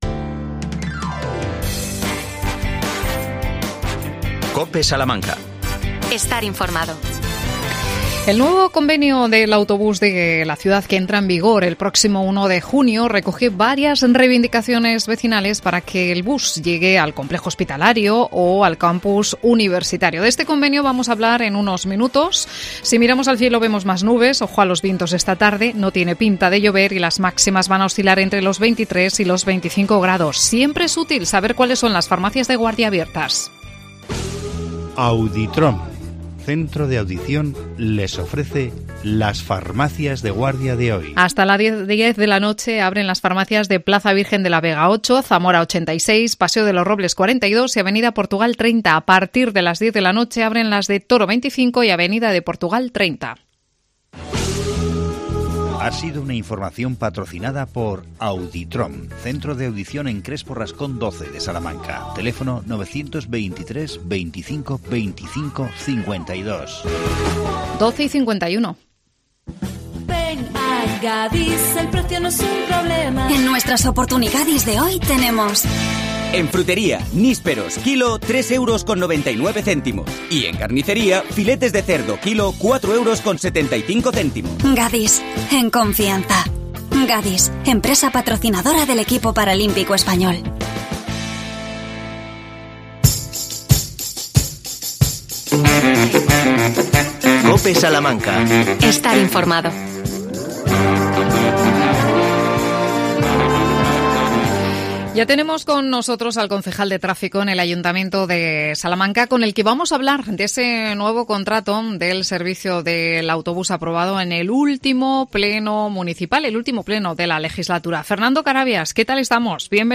AUDIO: Nuevo convenio del bus urbano en Salamanca. Explica las novedades el concejal de Tráfico Fernando Carabias.